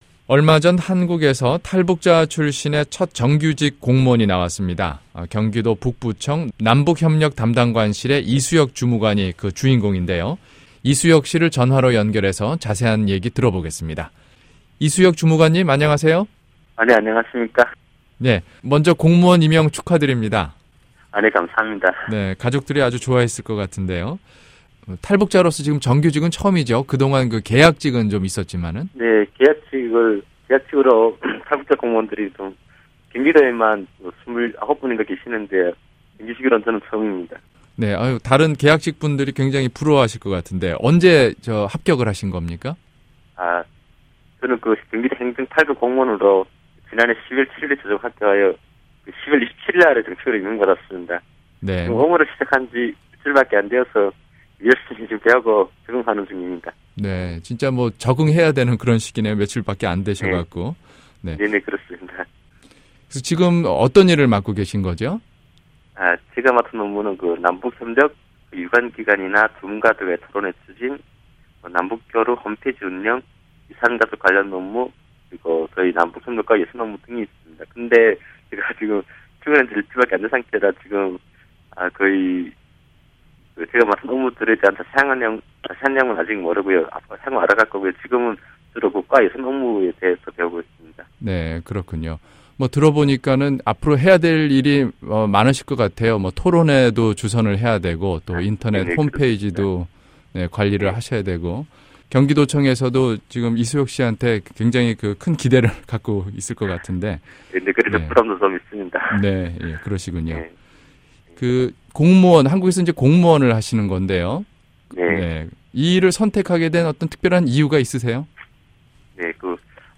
[인터뷰]
전화로 연결해